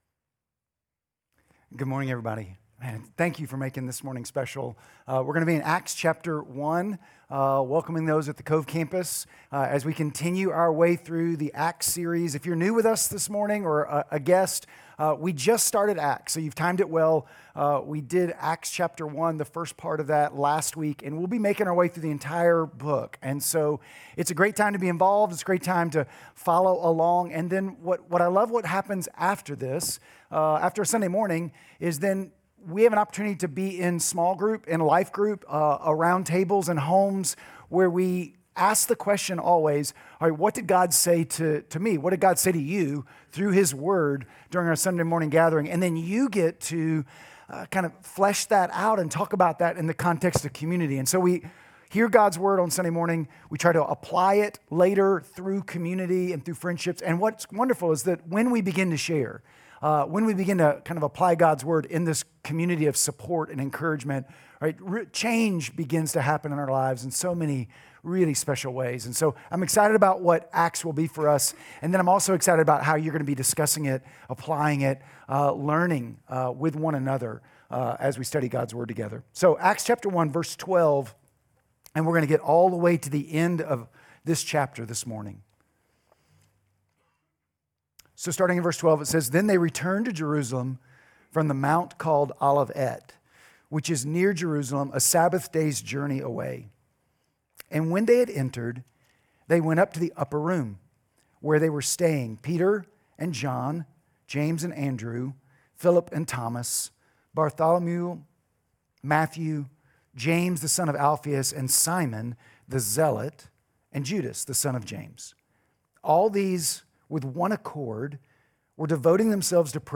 Sermon Audio Sermon Notes…